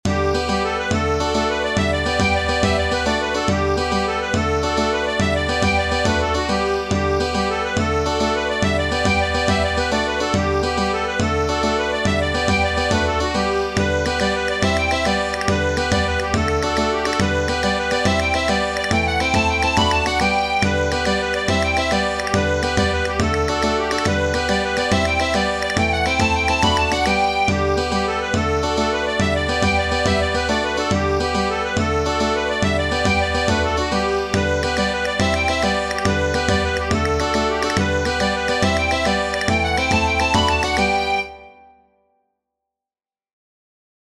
Tradizionale Genere: Folk "The Kesh Jig" è un'antica giga irlandese conosciuta in tutto il mondo anche con altri nomi: "Castle", "Kerrigan's", "The Kesh Mountain" ecc.